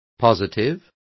Complete with pronunciation of the translation of positives.